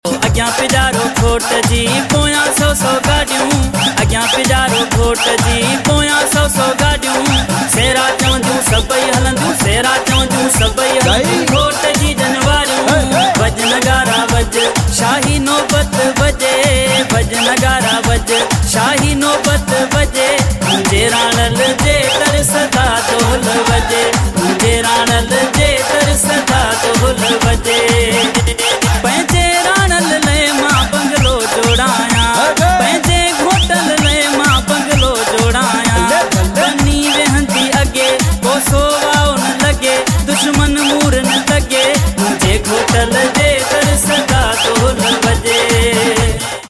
New Sindhi Mashup || Sindhi Sehra || Sindhi Lada